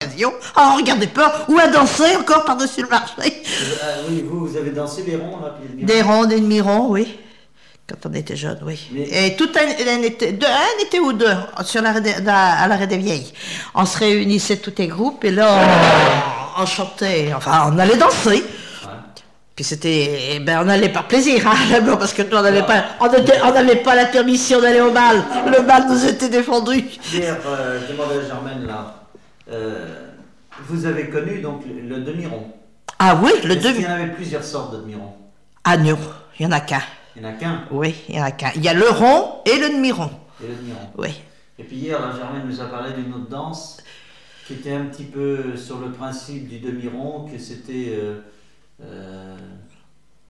témoignage sur l'apprentissage des chansons, de la danse, l'usine et des chansons
Catégorie Témoignage